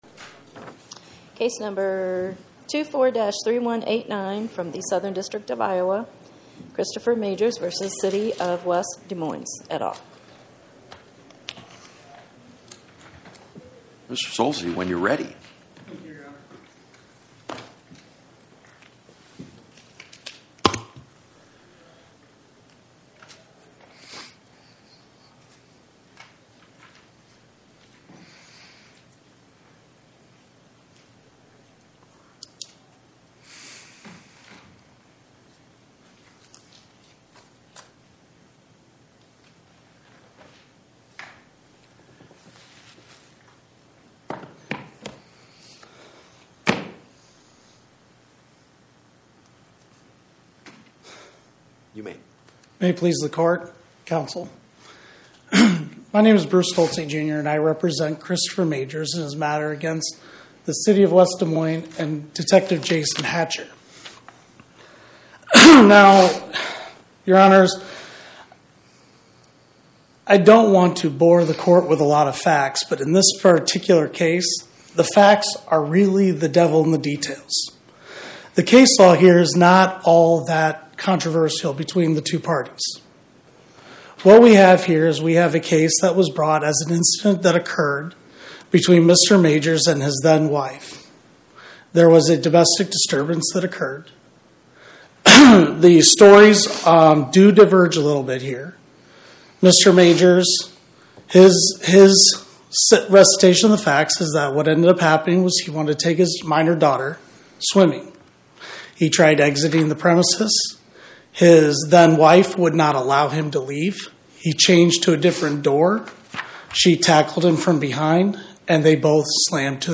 Oral argument argued before the Eighth Circuit U.S. Court of Appeals on or about 01/14/2026